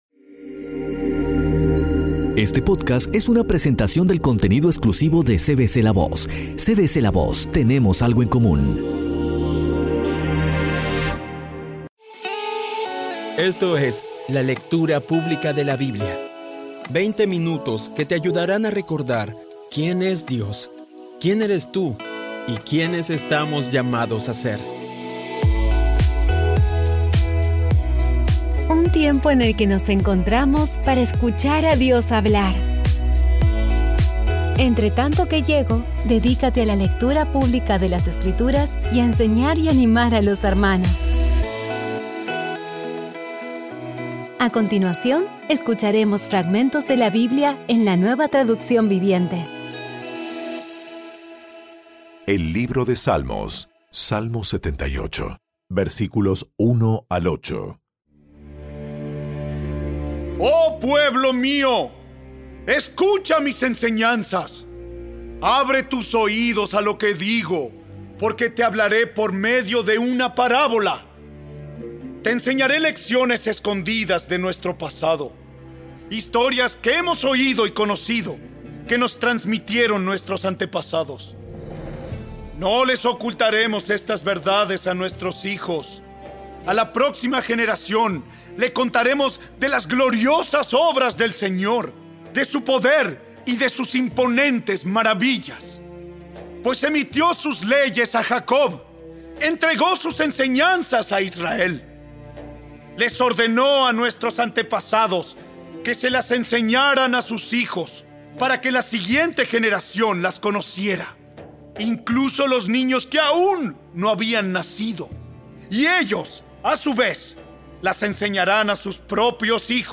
Audio Biblia Dramatizada Episodio 182
Poco a poco y con las maravillosas voces actuadas de los protagonistas vas degustando las palabras de esa guía que Dios nos dio.